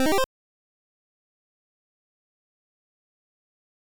snd_jump.wav